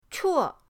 chuo4.mp3